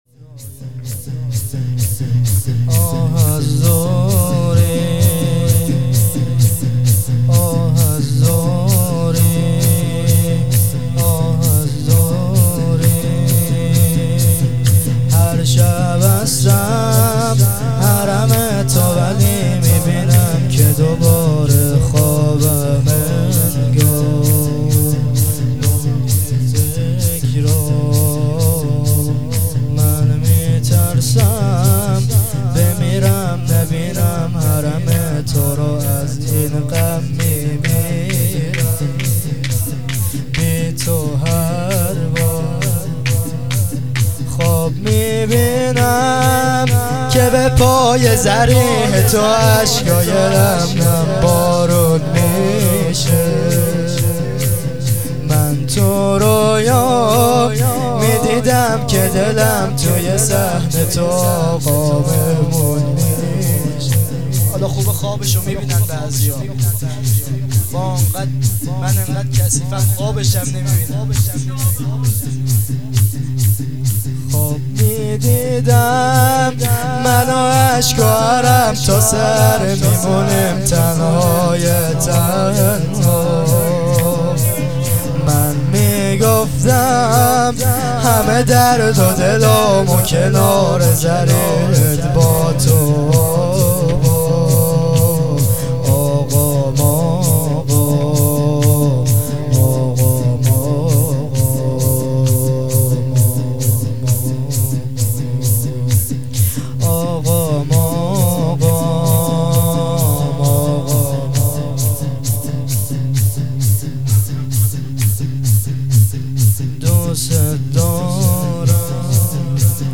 هیئت فرهنگی مذهبی فاطمیون درق
شهادت امام جواد علیه السلام ۹۸.۵.۱۰